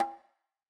ITA Conga 2.wav